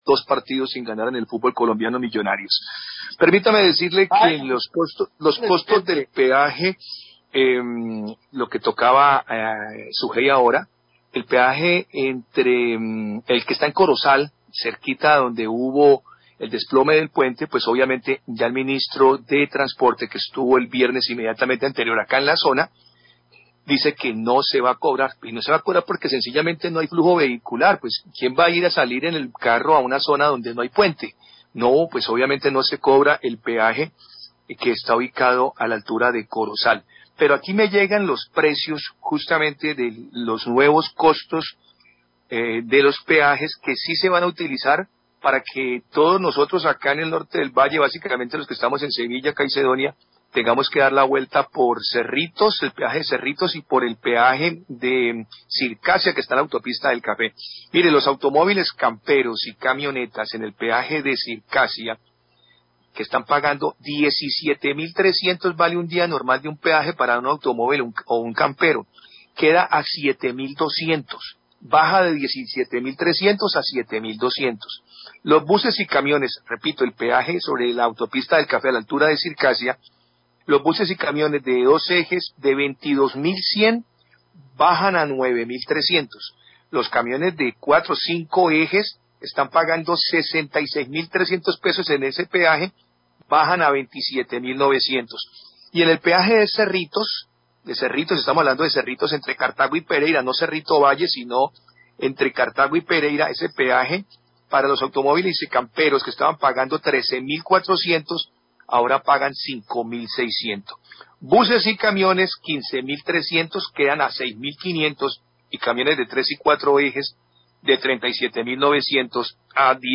Periodista informa la reducción de los valores de peajes en Cerritos y Circasia
Radio